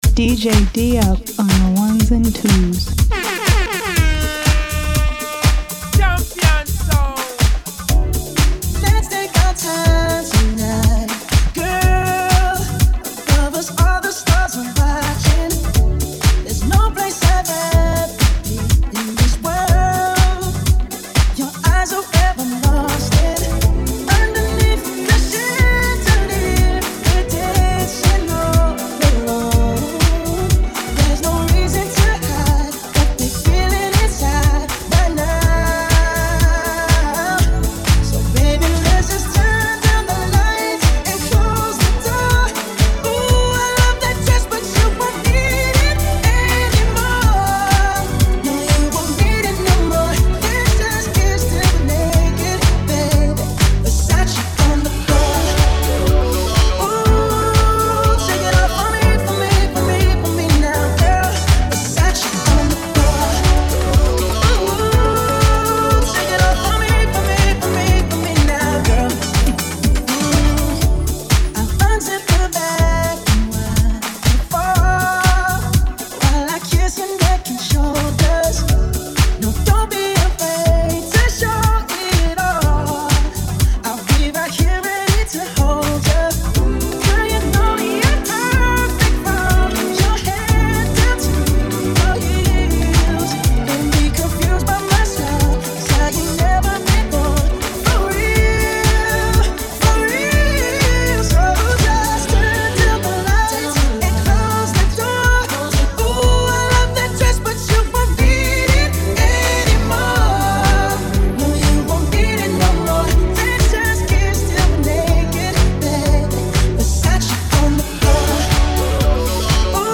House, Dance, Pop, Remixes